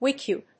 音節wik・i・up 発音記号・読み方
/wíki`ʌp(米国英語)/